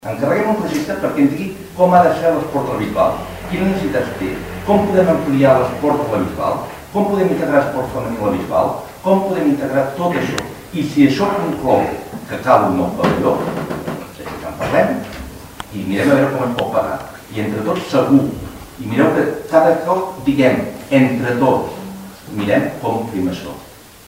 En sentit semblant a l’alcalde Sais es van pronunciar Josep Maria Castells de la CUP